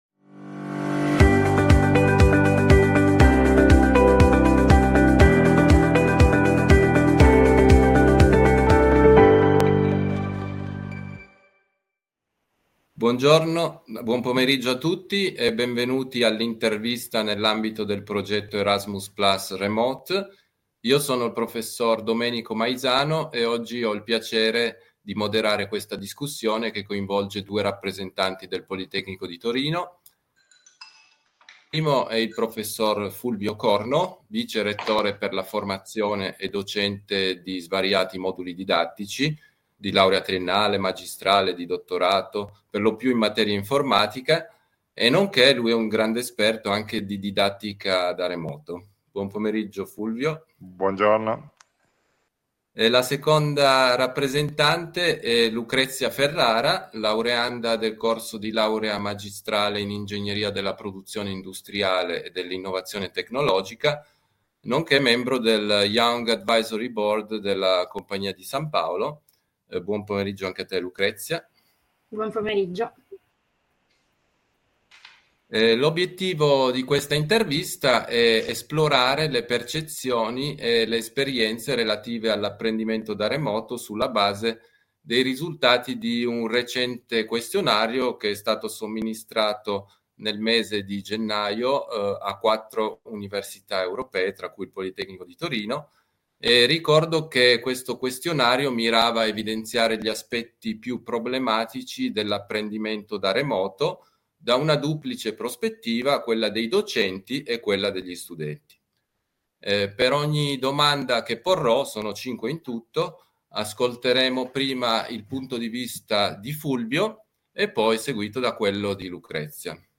In-Depth Interviews